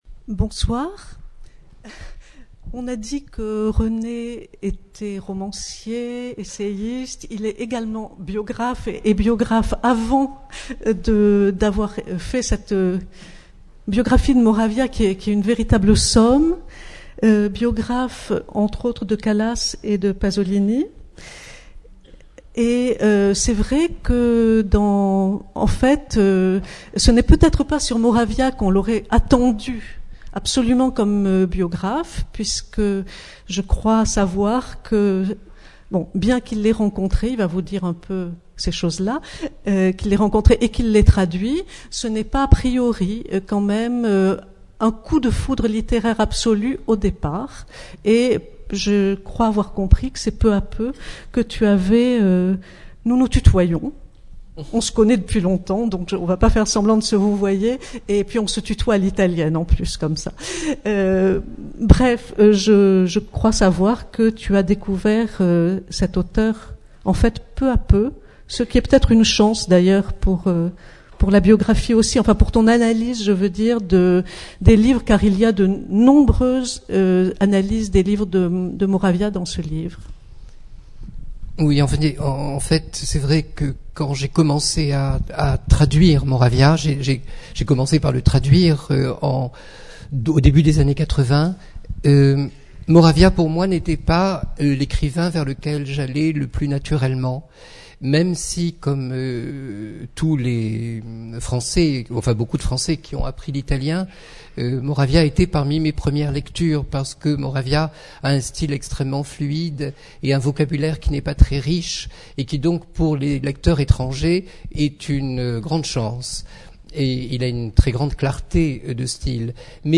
Personne interviewée' en grand format /5 0 avis Rencontre avec René de Ceccatty Date de publication ou de production : 2010 Ceccatty, René de (1952-....).
Rencontre littéraire